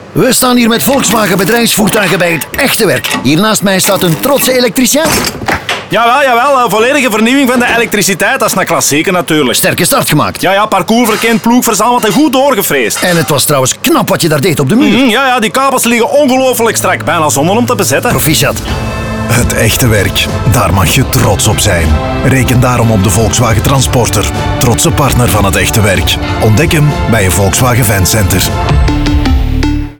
En ook op radio staan de trotse vakmensen centraal in verschillende reeksen van 3 radiospots waarin vakmensen met trots over hun job praten als waren het fiere sportmannen na een topprestatie.
Elektricien_NL.mp3